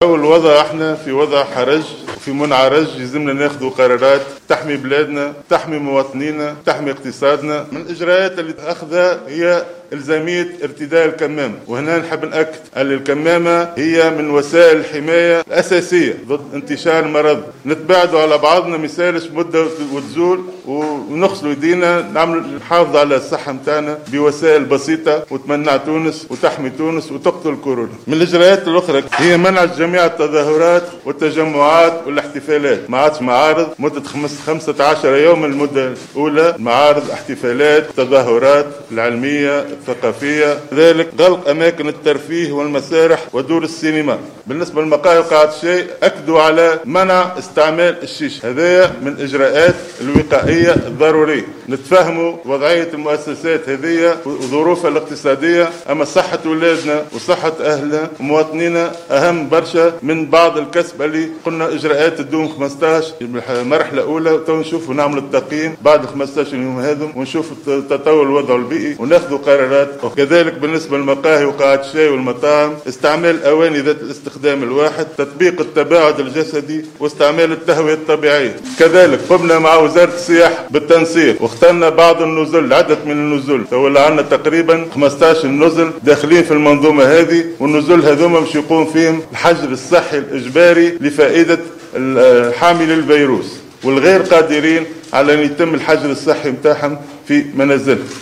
قال وزير الصحة فوزي المهدي في ندوة صحفية مشتركة ظهر اليوم الاثنين 5 اكتوبر 2020...